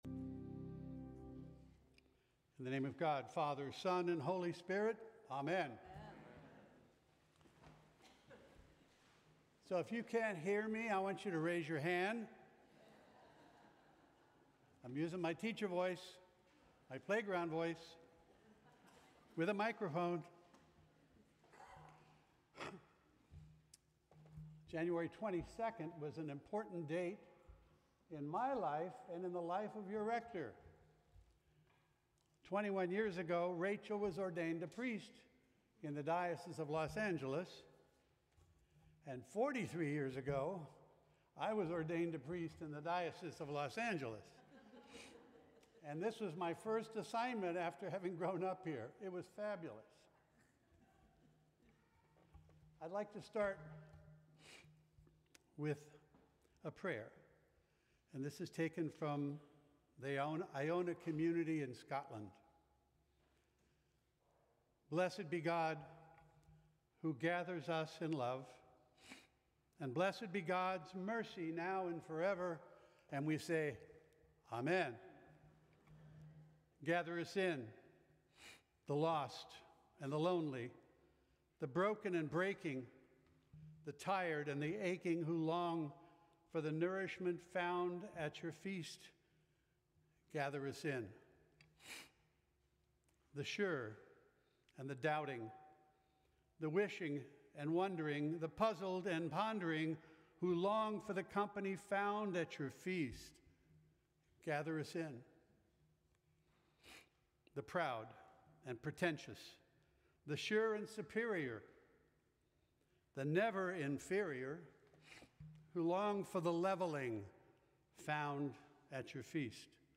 Sermons from St. Cross Episcopal Church Third Sunday after the Epiphany